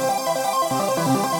Index of /musicradar/shimmer-and-sparkle-samples/170bpm
SaS_Arp02_170-C.wav